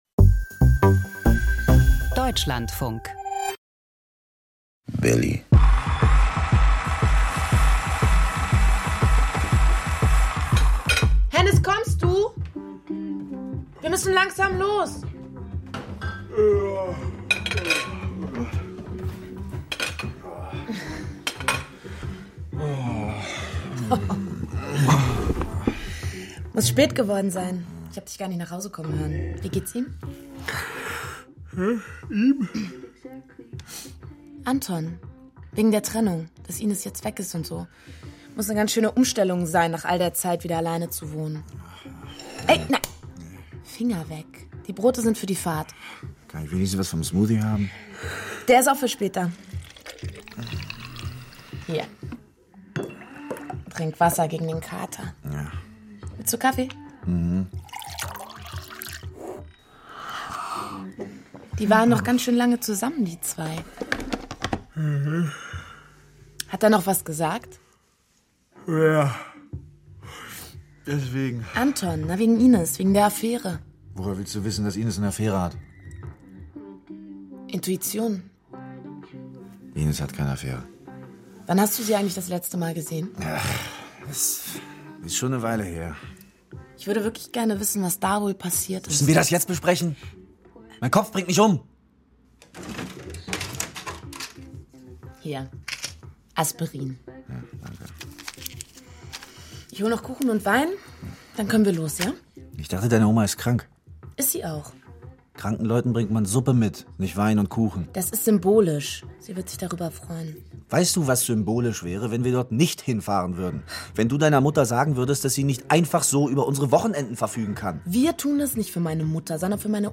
Krimi-Hörspiel